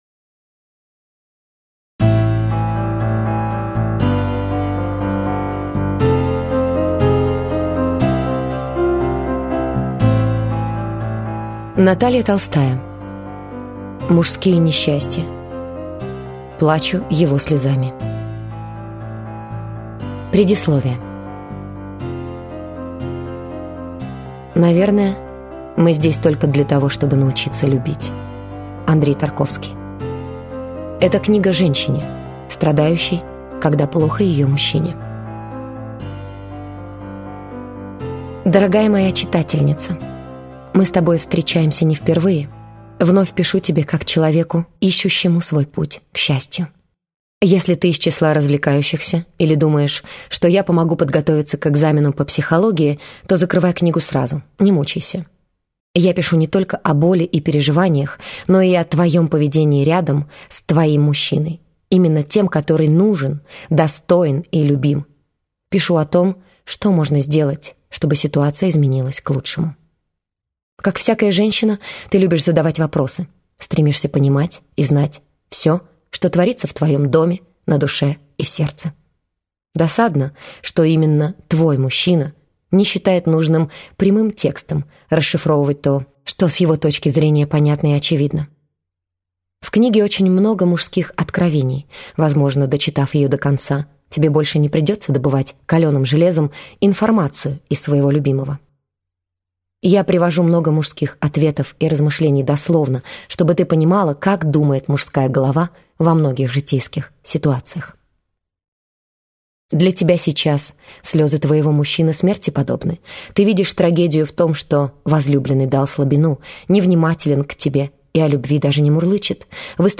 Аудиокнига Мужские несчастья. Плачу его слезами | Библиотека аудиокниг